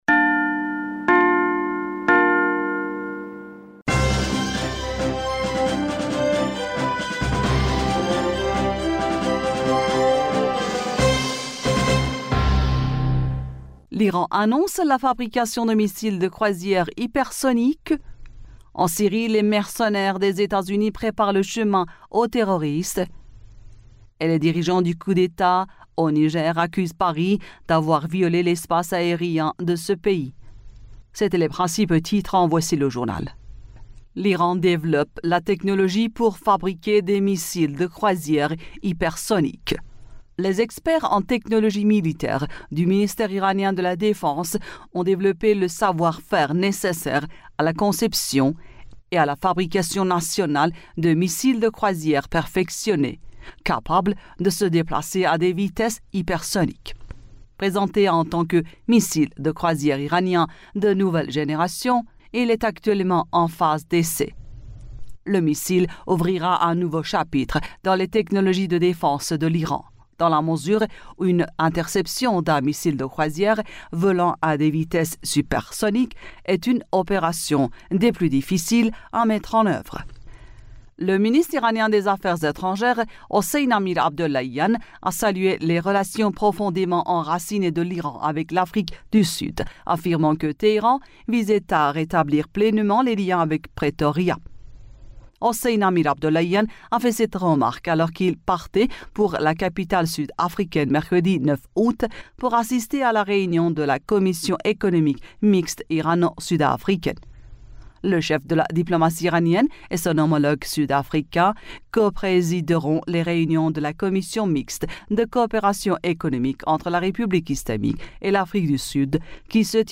Bulletin d'information du 10 Aout 2023